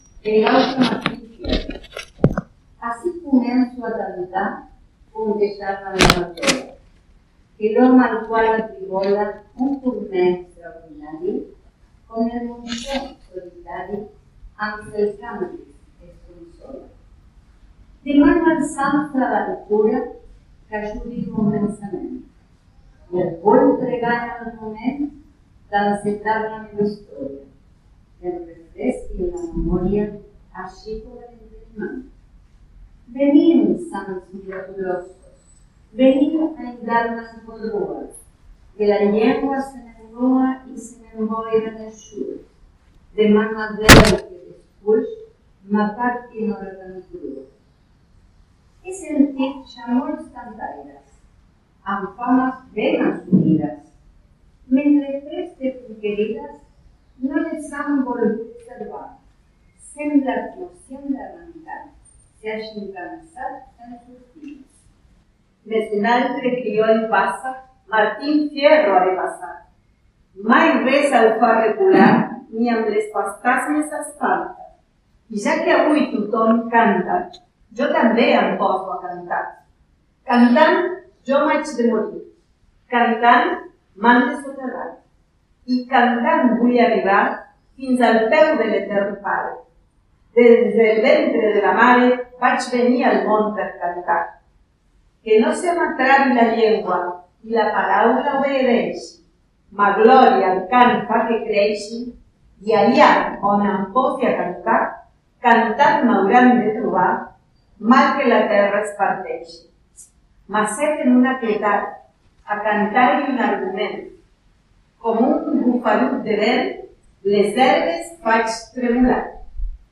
leen el primer canto de El gaucho Martín Fierro en idioma catalán, traducido por Enric Martí i Muntaner.
Evento: Jornadas Políglotas Martín Fierro (City Bell, 1° de diciembre de 2023)